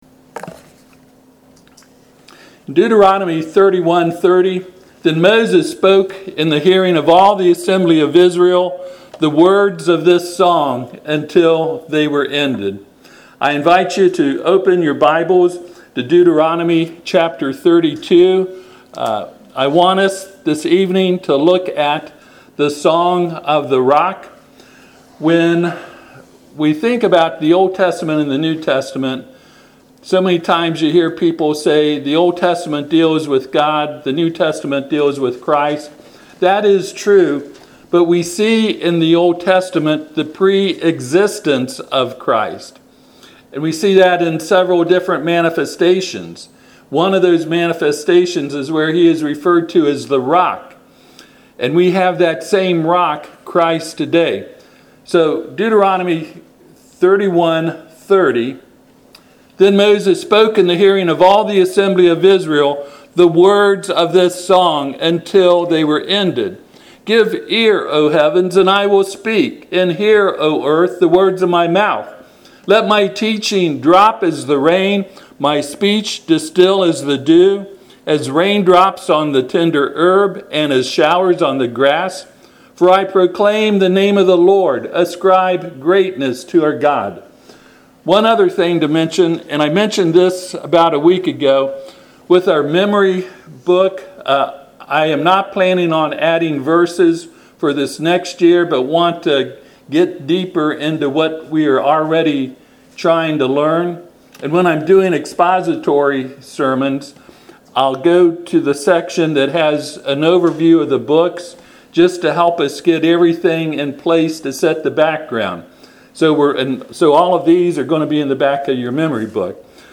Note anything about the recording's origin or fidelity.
Passage: Deuteronomy 32:1-4 Service Type: Sunday AM